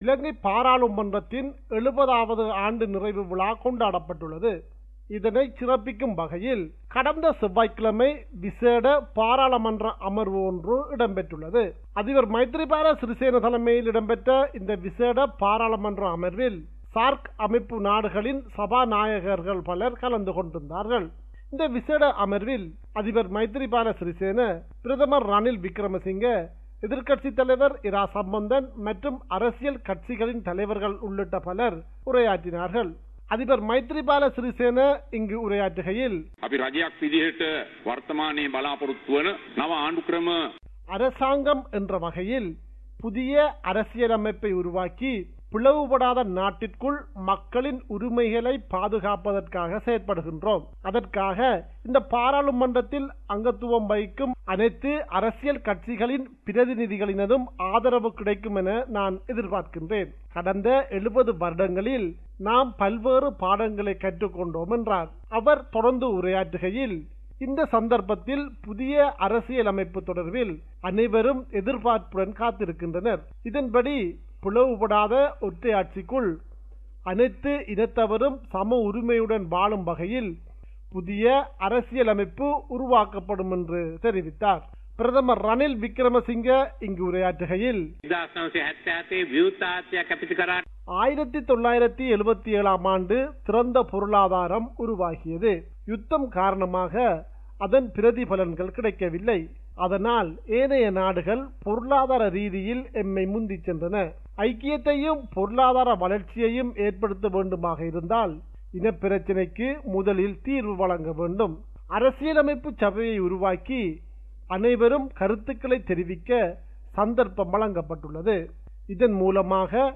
compiled a report focusing on major events/news in North & East / Sri Lanka.